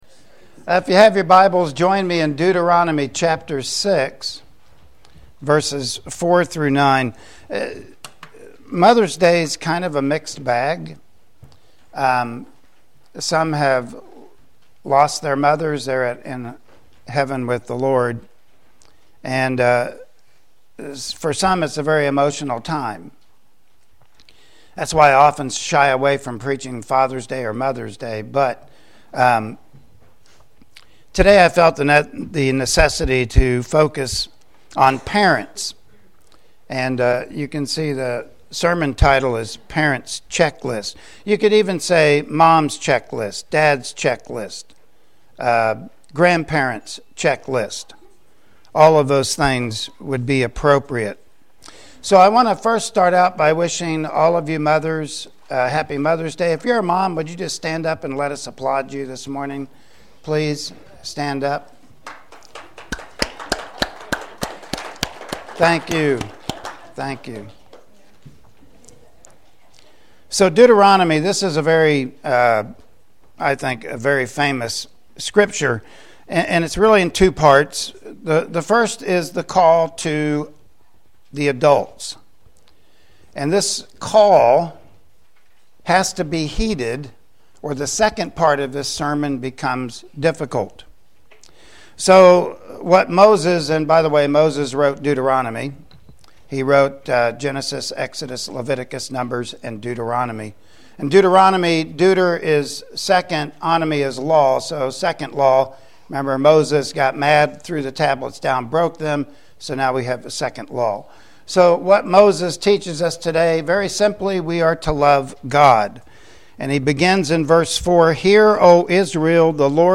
Mother's Day Passage: Deuteronomy 6:4-9 Service Type: Sunday Morning Worship Service Topics